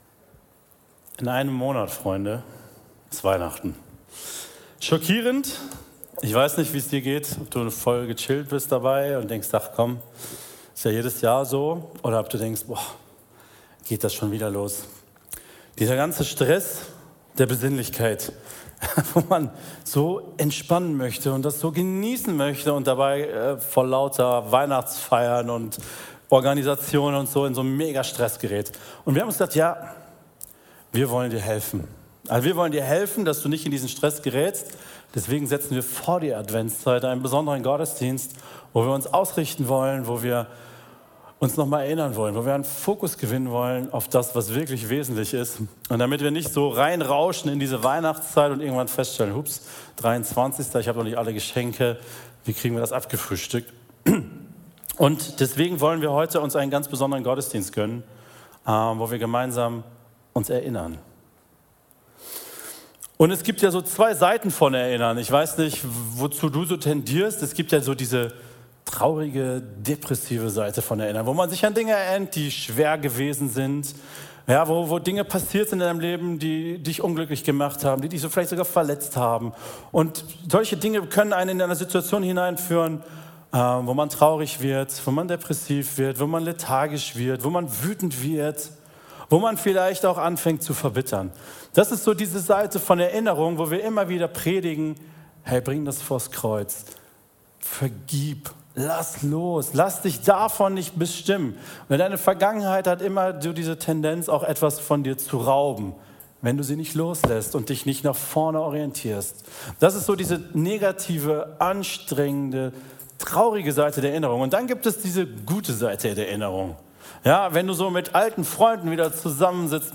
Predigten der Treffpunkt Leben Gemeinde, Erkrath